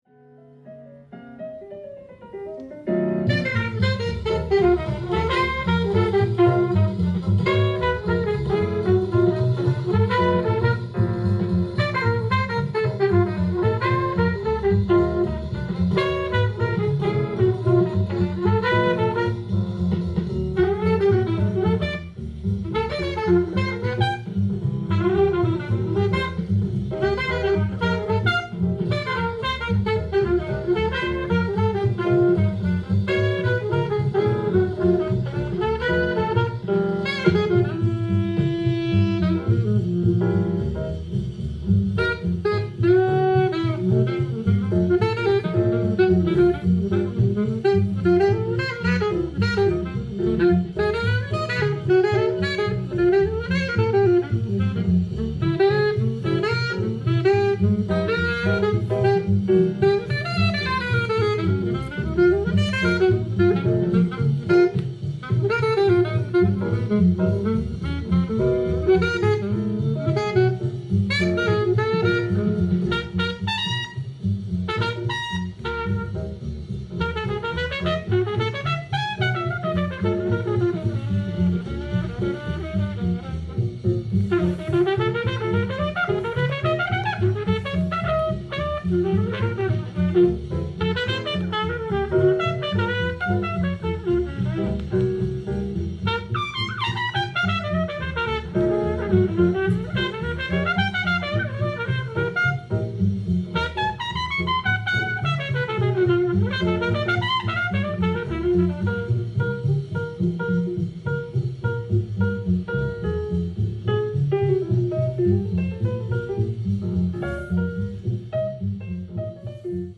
ジャンル：JAZZ-ALL
店頭で録音した音源の為、多少の外部音や音質の悪さはございますが、サンプルとしてご視聴ください。
インサート無し　盤は良好だがレコーディング時のシュー音有